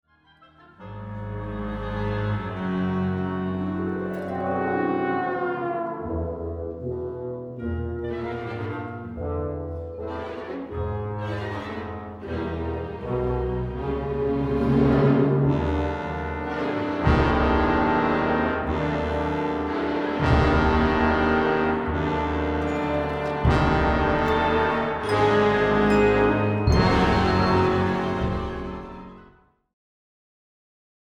Orchestre